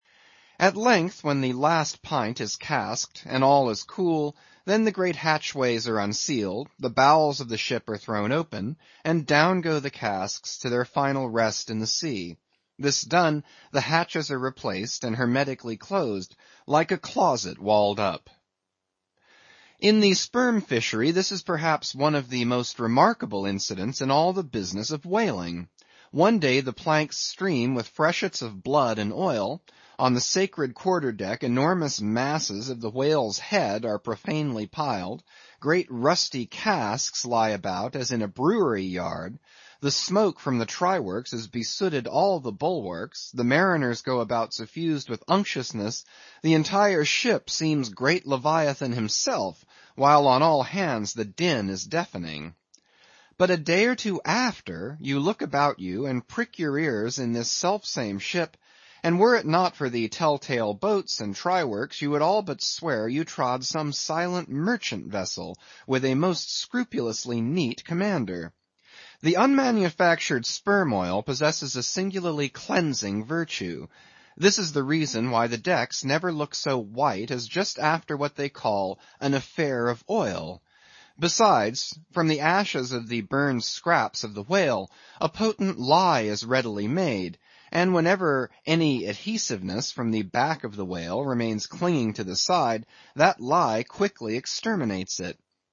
英语听书《白鲸记》第824期 听力文件下载—在线英语听力室